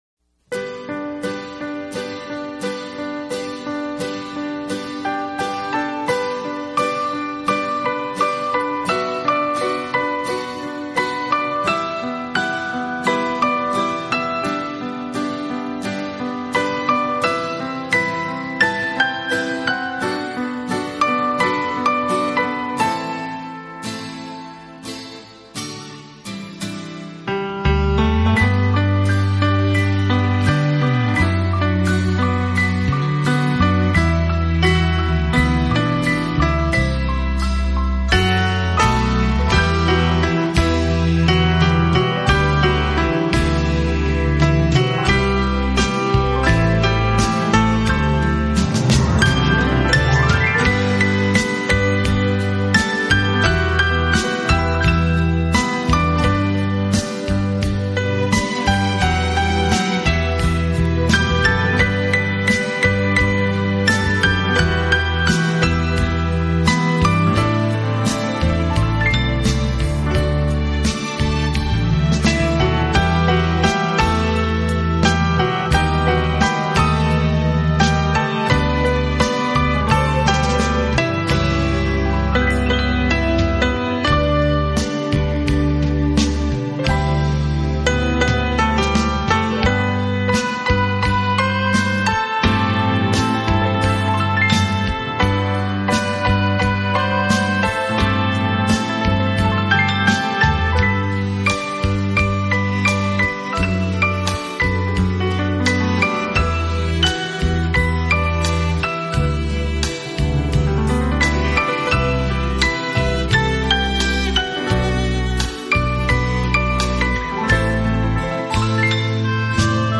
这是一套非常经典的老曲目经过改编用钢琴重新演绎的系列专辑。
本套CD全部钢琴演奏，